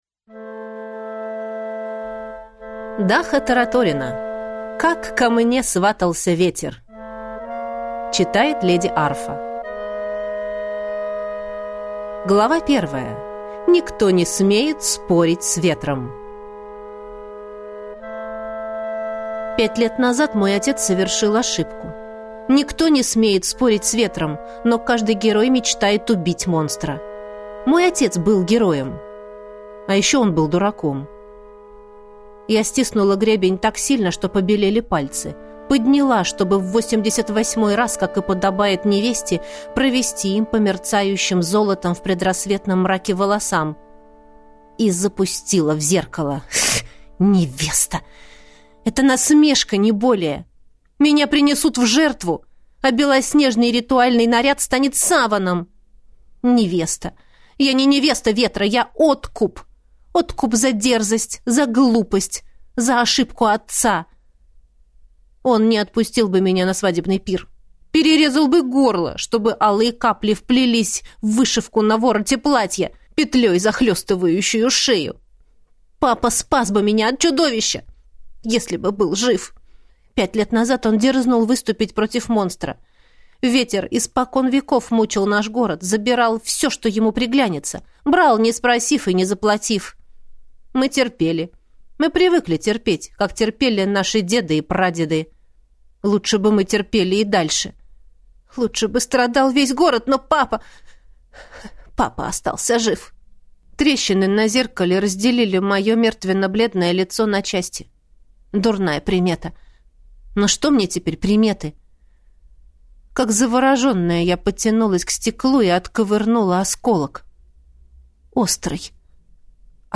Аудиокнига Как ко мне сватался Ветер | Библиотека аудиокниг